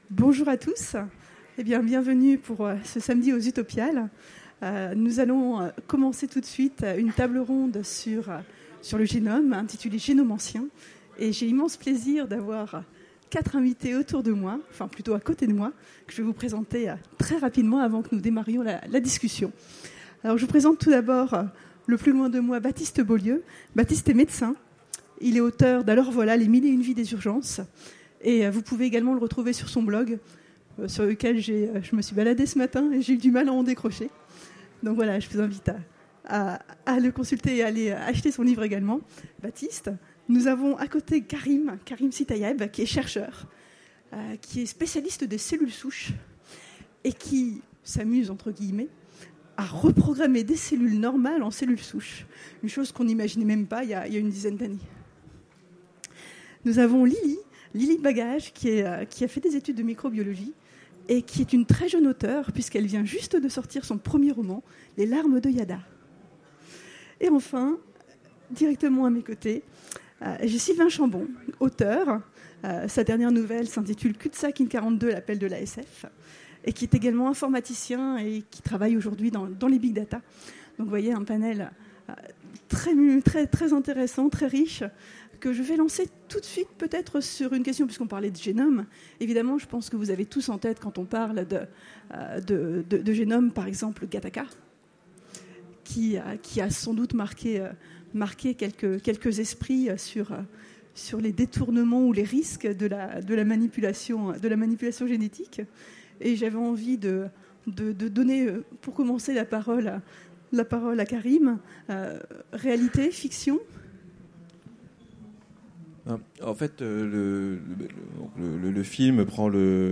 Utopiales 2017 : Conférence Génomancien